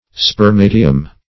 spermatium - definition of spermatium - synonyms, pronunciation, spelling from Free Dictionary
Search Result for " spermatium" : The Collaborative International Dictionary of English v.0.48: Spermatium \Sper*ma"ti*um\, n.; pl.